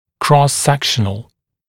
[krɔs-‘sekʃənl][крос-‘сэкшэнл]поперечный; перекрестный (о данных, сравнениях)